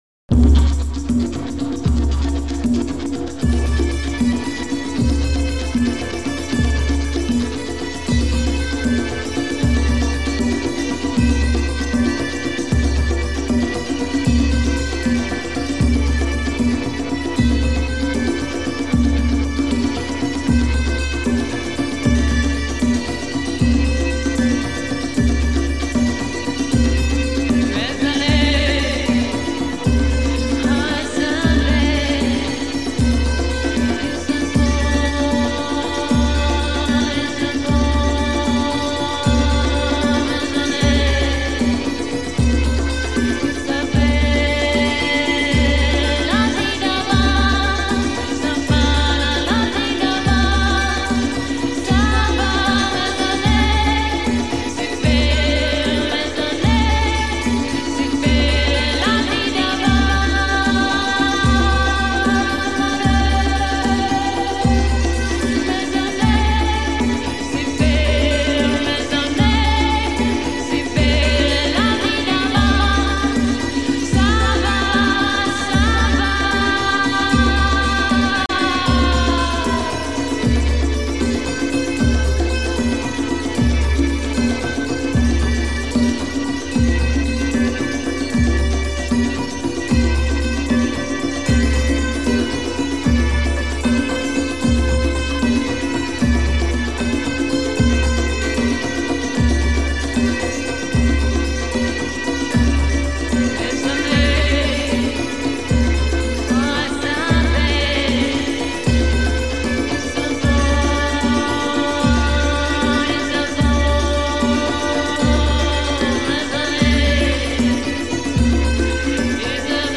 BBC Session
drum driven, chanting and ambient nature
mesmerizing grandeur and solemn beauty.